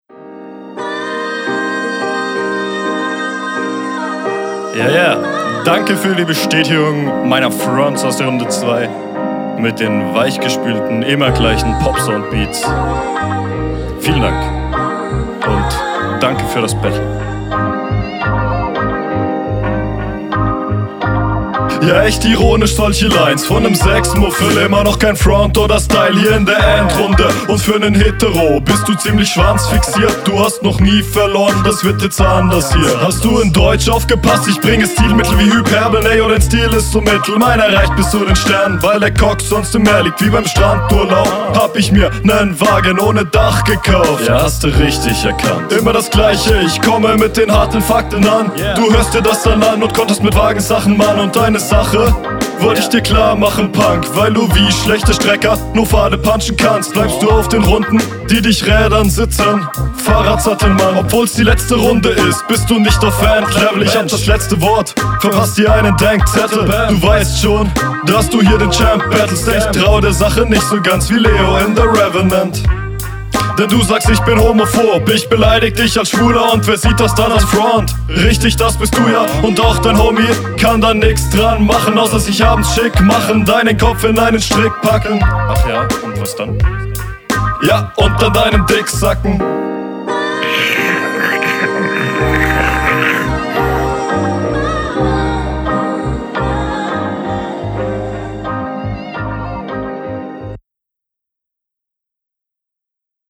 Sound und Flow: Stimme wieder cool.